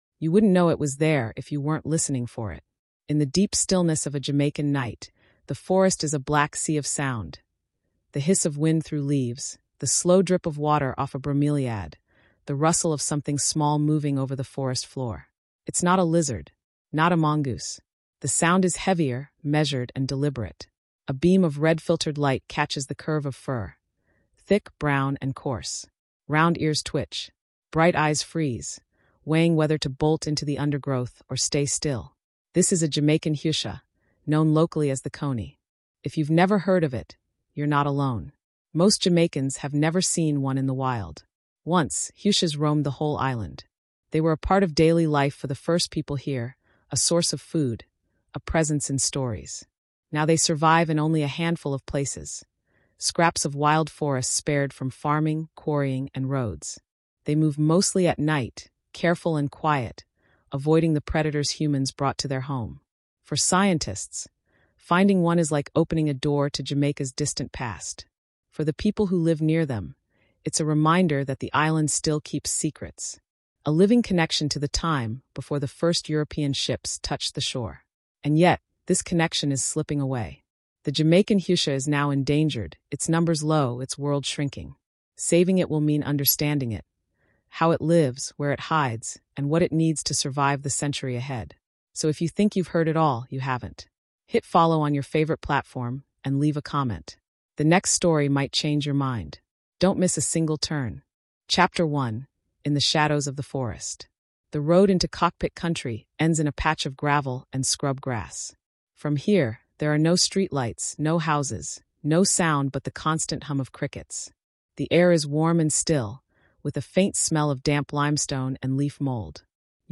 In this Smithsonian-style wildlife documentary, we journey deep into Cockpit Country and beyond to uncover the hutia’s secret nightlife, its significant role in Taíno culture and Caribbean history, and the modern challenges it faces. Through immersive field observations, expert interviews, and community voices, we explore the animal’s vital role in Jamaica’s forest ecosystem and the quiet rescue efforts keeping it alive. This episode highlights themes of resilience and cultural memory, emphasizing the fight to protect a species that has survived for centuries in the Caribbean’s shadowed hills.